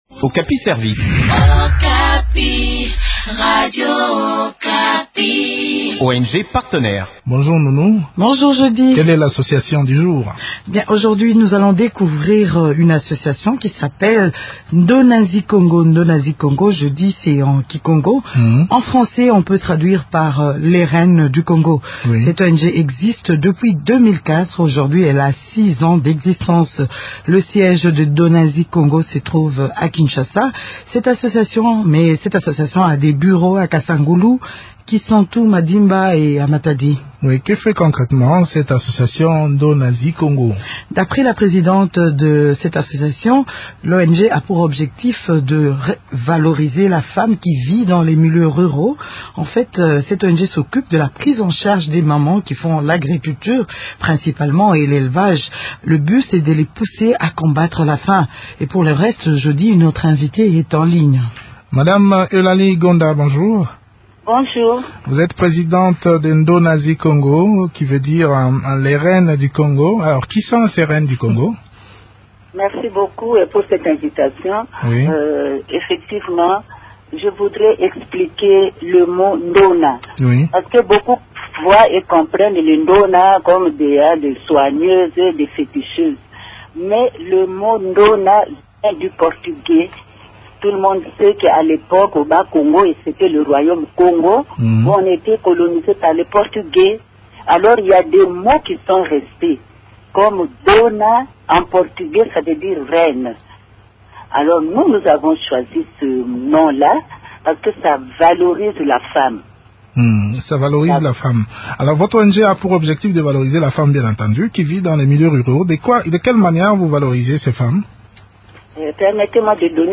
Découvrons les activités de cette ONG dans cet entretien